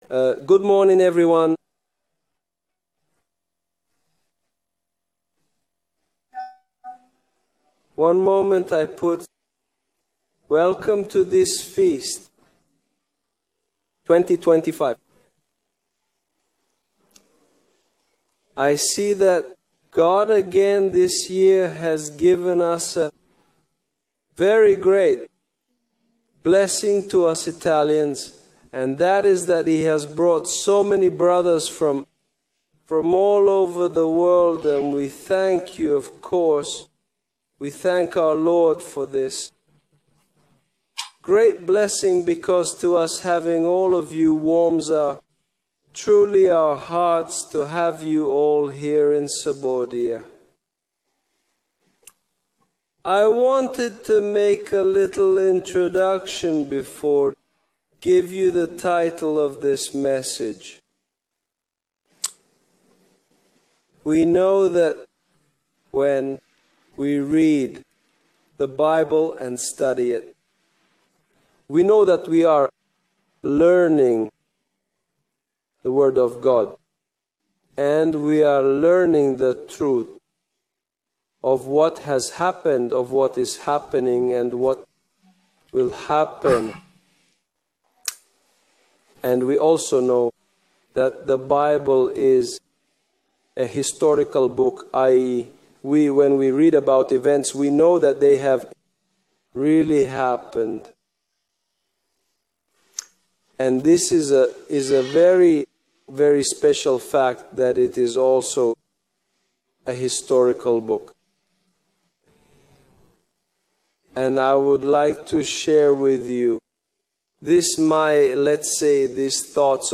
FoT 2025 Sabaudia (Italy): 4th day Given on Oct 10